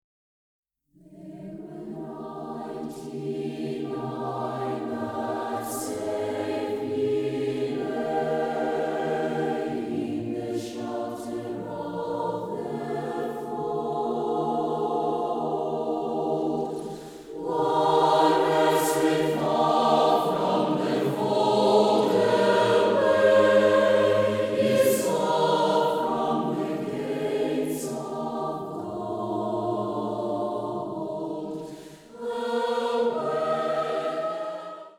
gemischter Chor